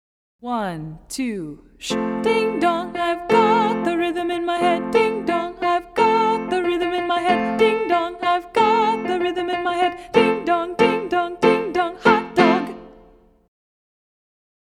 In fact, there are only 2 notes in the melody of this song.  This simple melody puts the focus on the rhythm, so as you play it, really make the rhythm count!